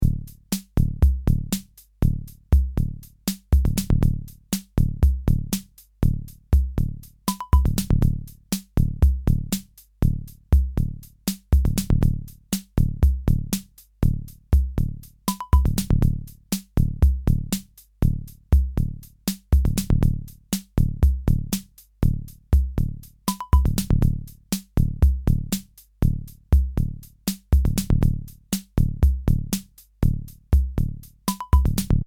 Bucle de percusión electrónica
Música electrónica
percusión
repetitivo
rítmico
sintetizador